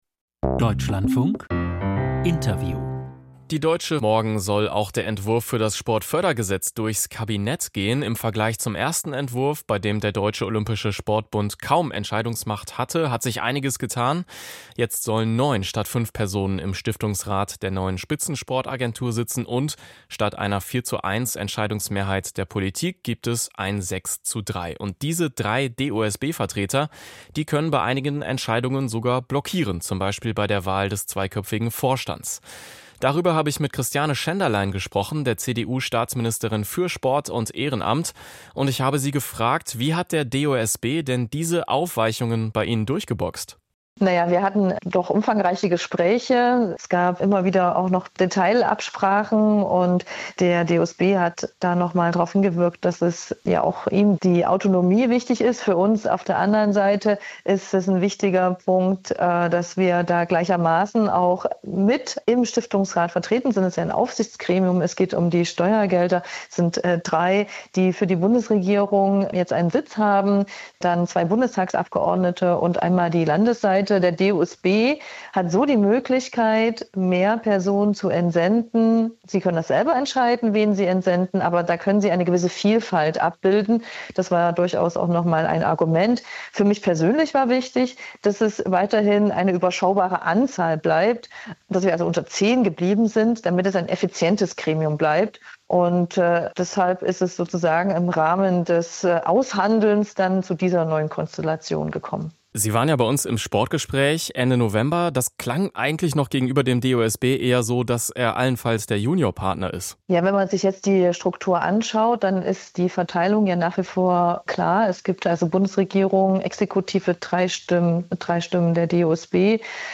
Interview Staatsministerin Schenderlein (CDU) zu Sportfördergesetz im Kabinett